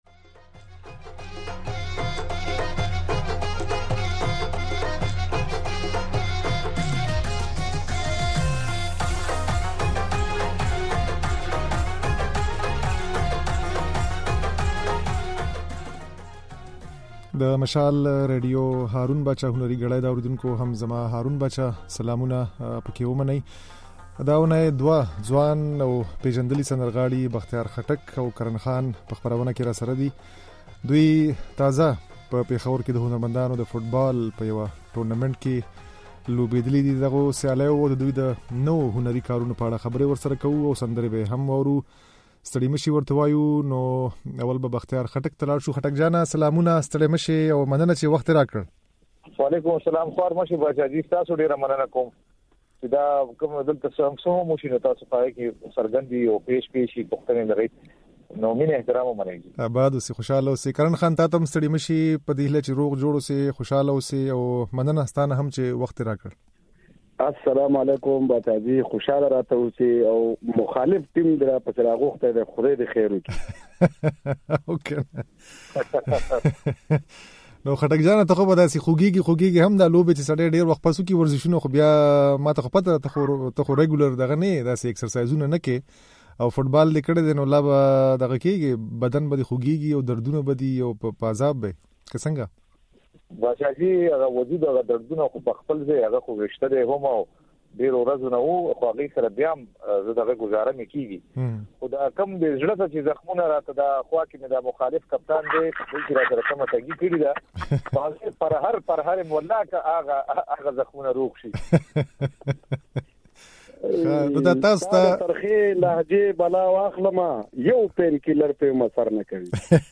د دې اوونۍ په “هارون باچا هنري ګړۍ” خپرونه کې مو له پېژندليو سندرغاړو بختيار خټک او کرن خان سره په پېښور کې د هنرمندانو ترمنځ د فوټسال سياليو په اړه خبرې کړې دي.
دوی وايي، د يو ورځني ټورنامېنټ هدف يې هنرمندانو ته مثبته انرژي ورکول او هنري کارونو ته د هغوی هڅول وو. په خپرونه کې د دې سندرغاړو د خبرو ترڅنګ د دوی سندرې هم اورېدای شئ.